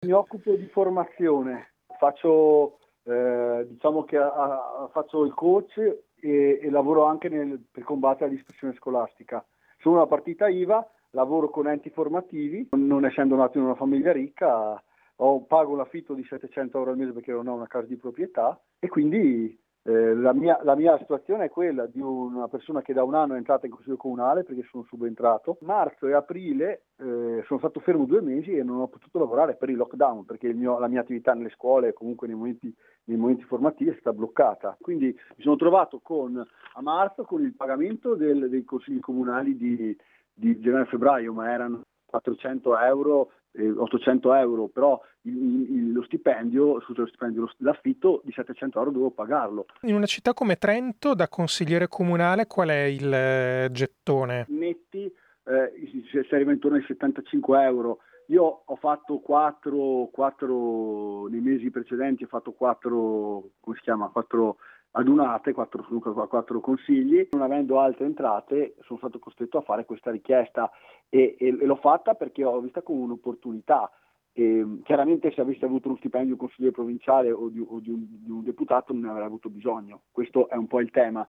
intervistato il consigliere comunale di Trento Jacopo Zannini